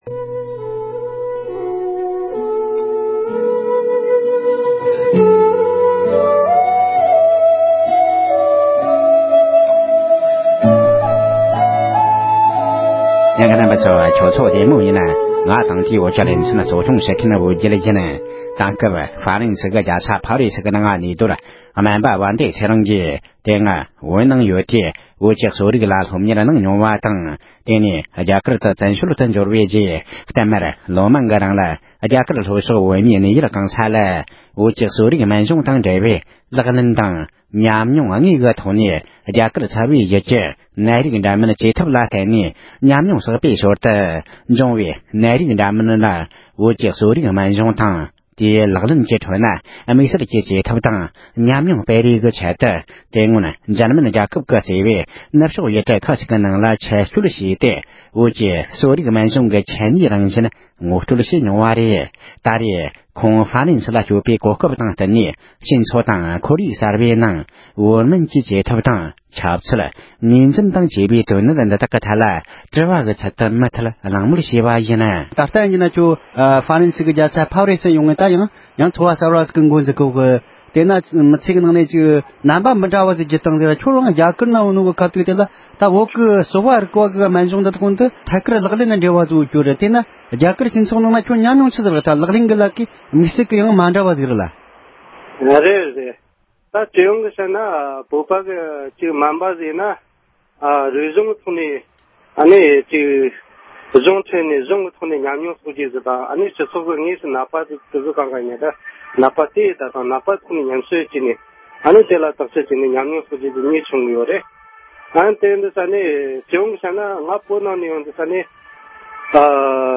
དེང་དུས་ཀྱི་སྤྱི་ཚོགས་ནང་བོད་ཀྱི་གསོ་རིག་དང་ལག་ལེན་བྱེད་ཐབས་བཅས་ཀྱི་སྐོར་གླེང་མོལ།